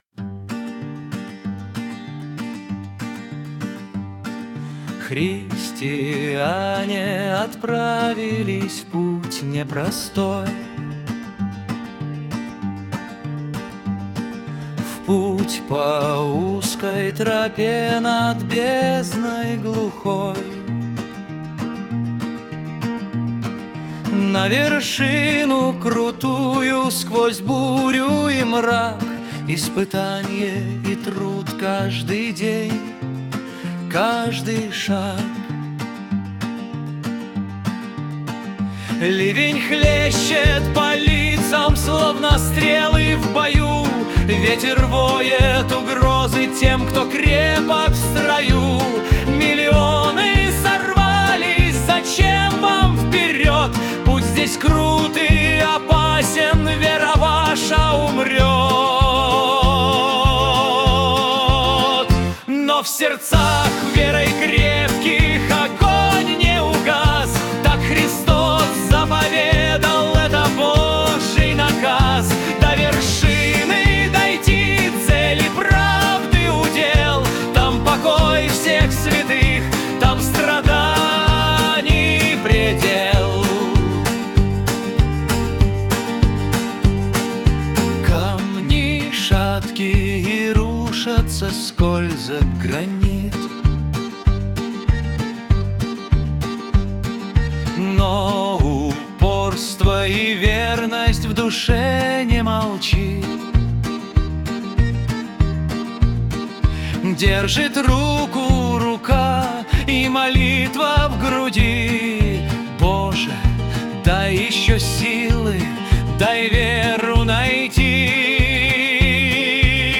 Нейросеть поёт Христу.
Представленные ниже песни были созданы с помощью нейронной сети на основе наших стихов